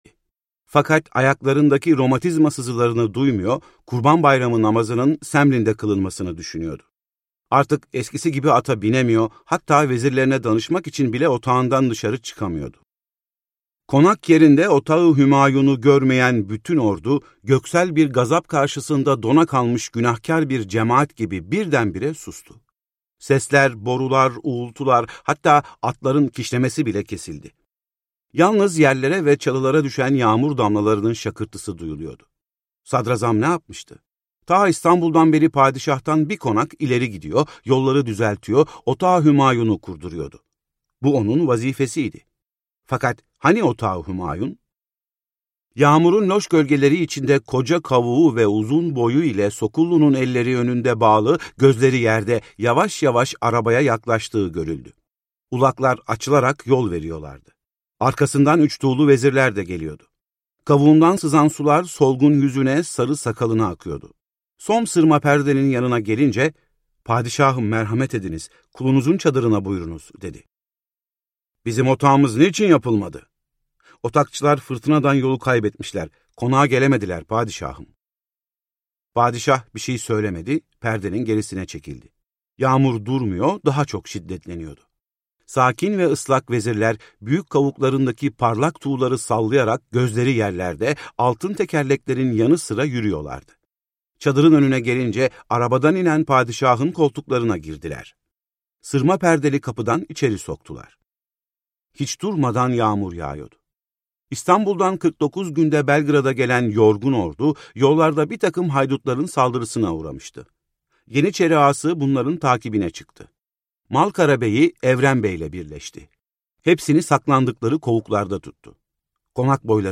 Ferman - Seslenen Kitap